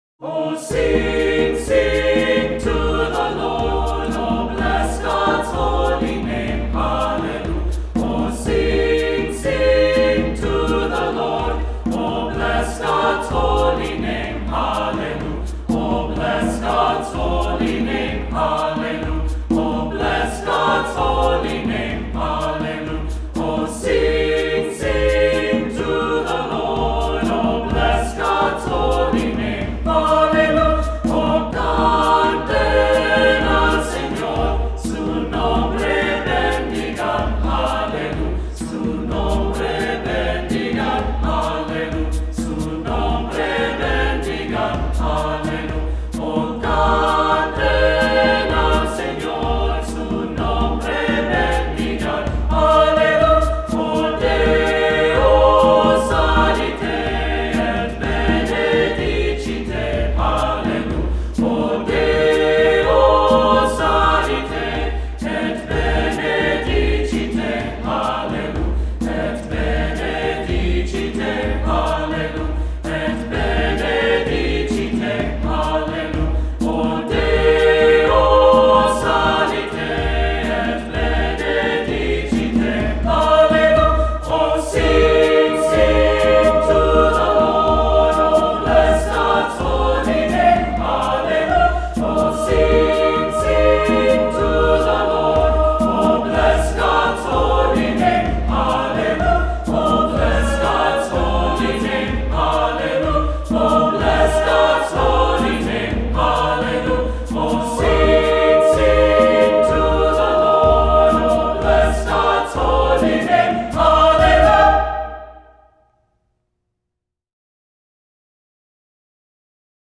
Voicing: SATB; assembly, descant